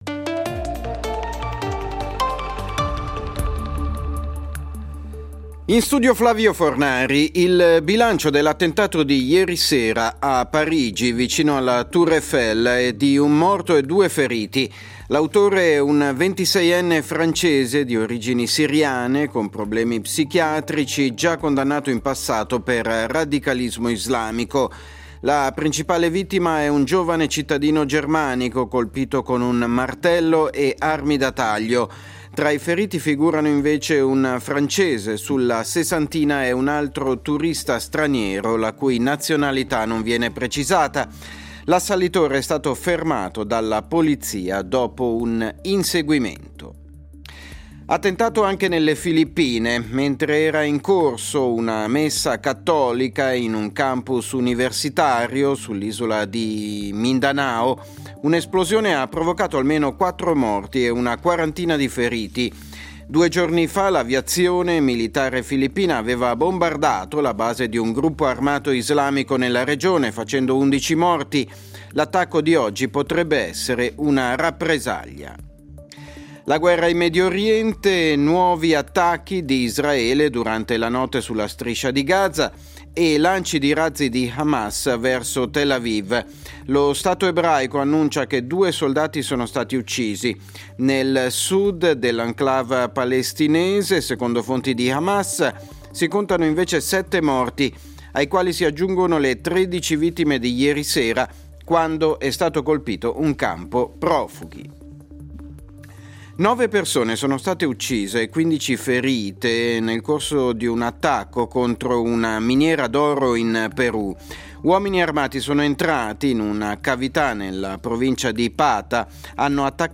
Notiziario delle 09:00 del 03.12.2023